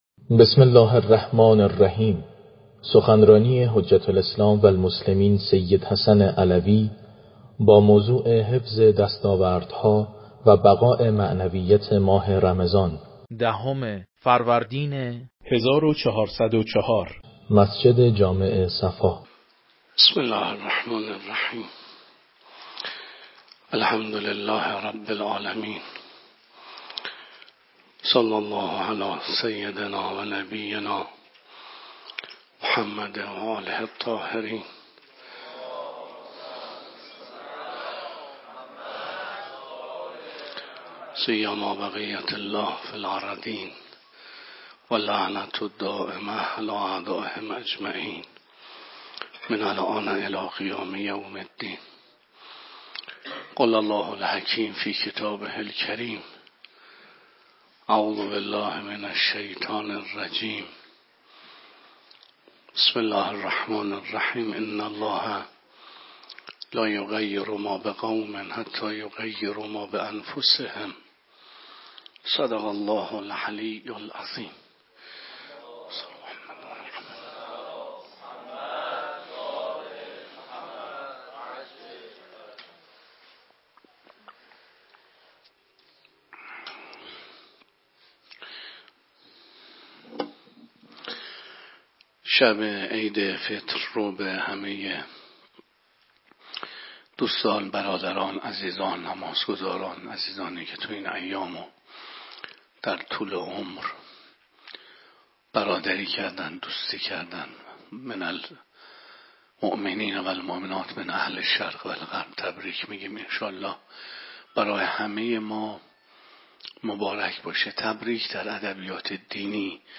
سخنرانی
مسجد جامع صفا